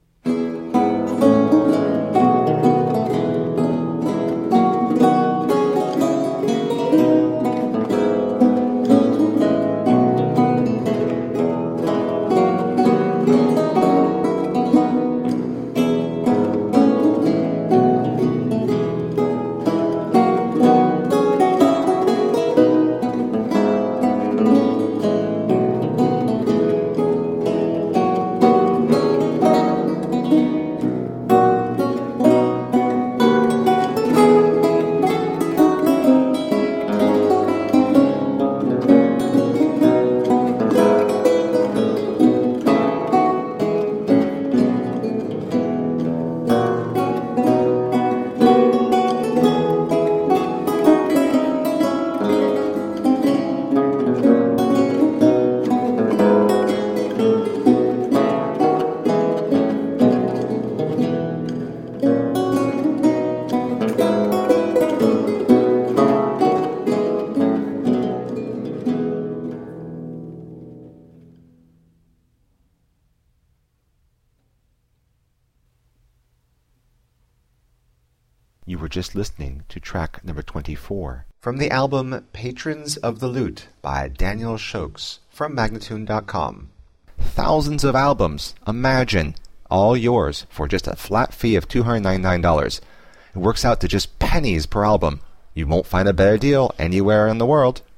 A feast of baroque lute.
Classical, Baroque, Instrumental